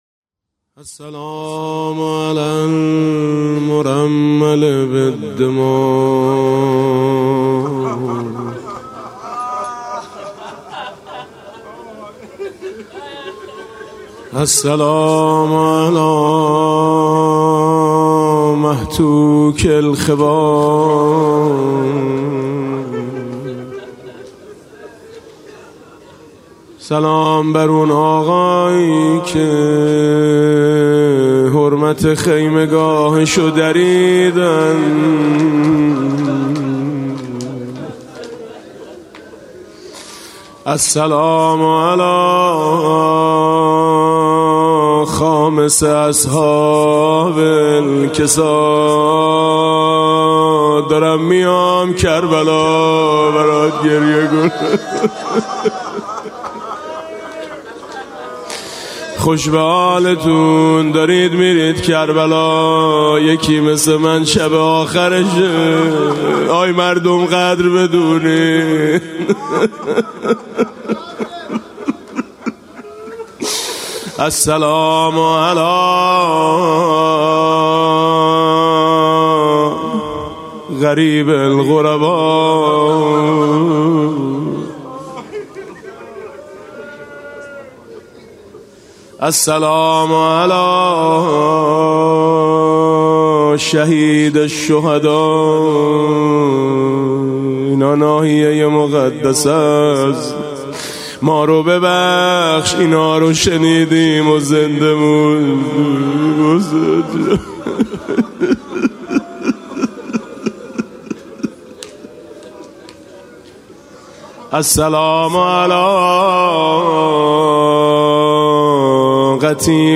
مسیر پیاده روی نجف تا کربلا [عمود ۹۰۹]
مناسبت: ایام پیاده روی اربعین حسینی
با نوای: حاج میثم مطیعی